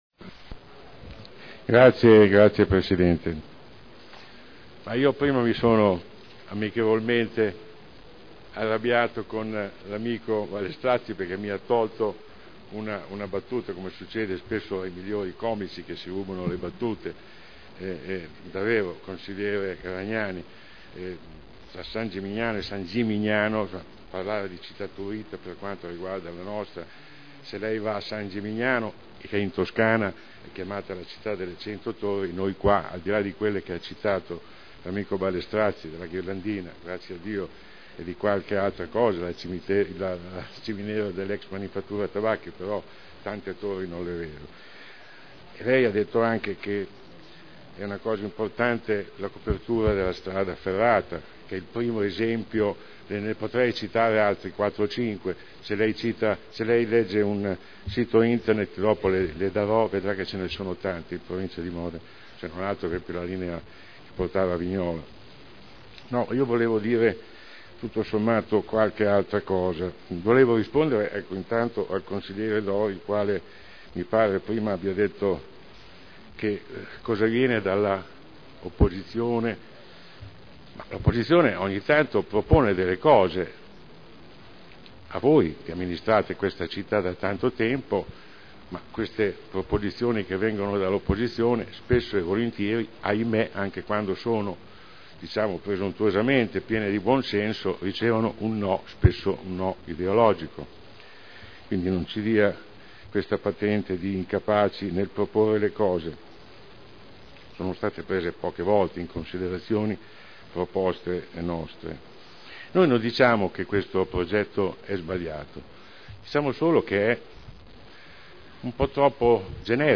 Sandro Bellei — Sito Audio Consiglio Comunale
Seduta del 01/02/2010. La Città della riqualificazione – Programma di riqualificazione urbana per il quadrante urbano di Modena Ovest – Approvazione del documento di indirizzo